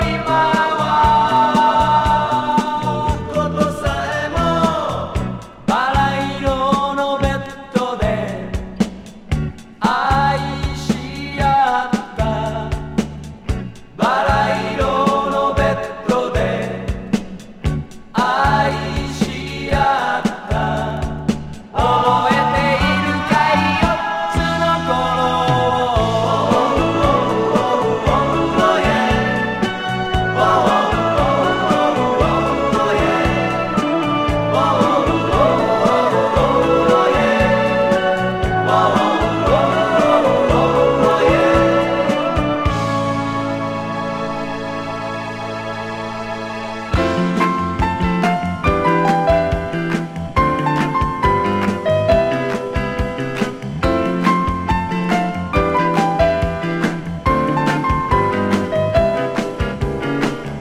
ジャパニーズ・グラムロック！